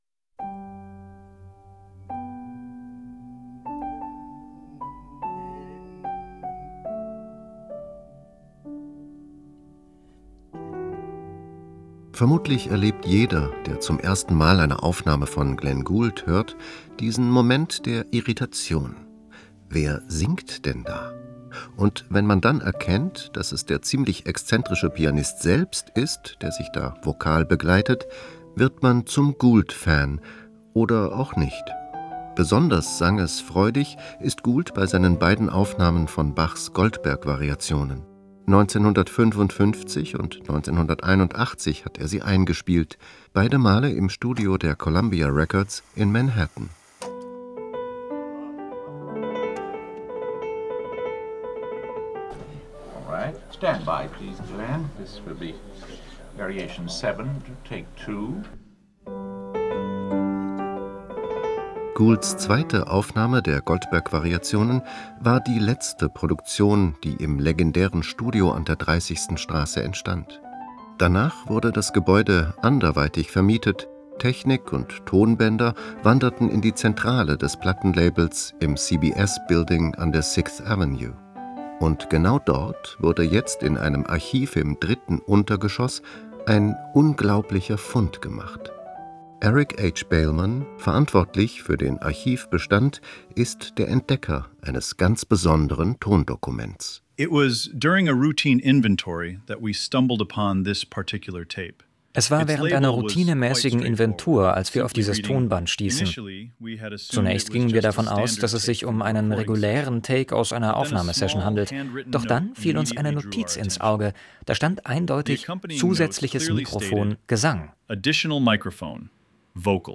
Musikthema